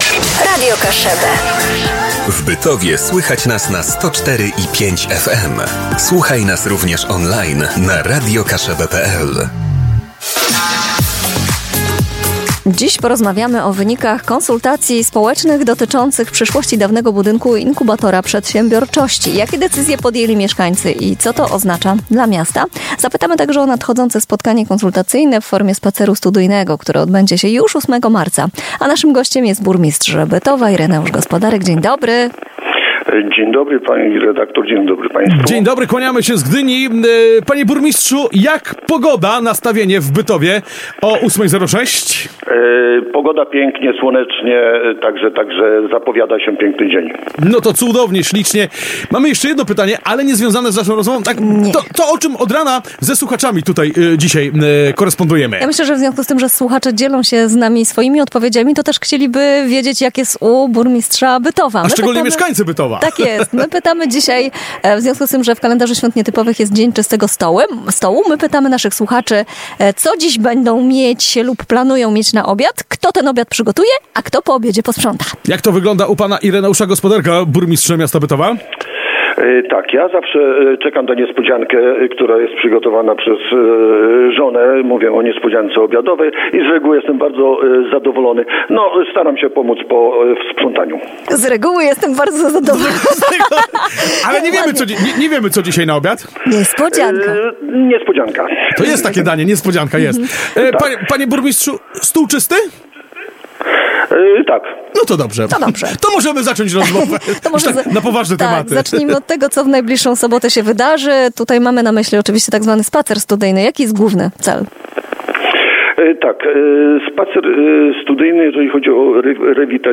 rozmowa_Ireneusz-Gospodarek-burmistrz-Bytowa.mp3